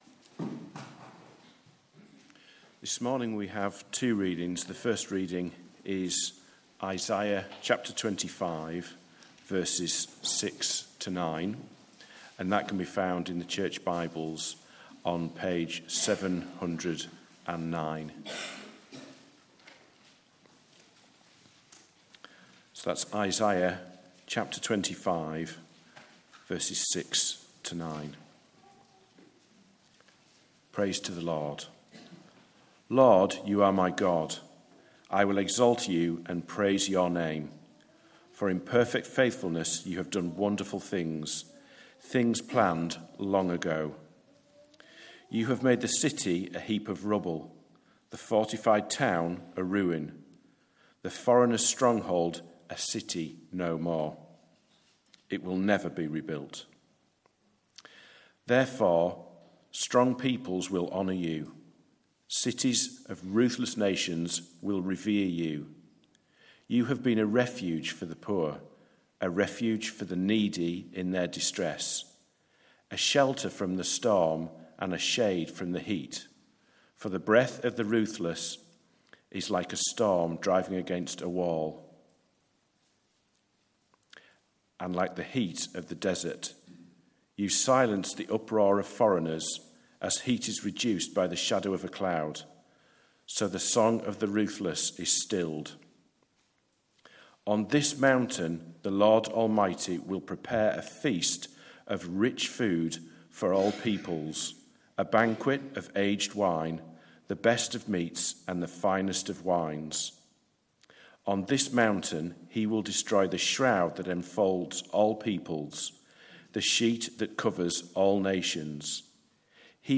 Service Type: Morning Service 9:15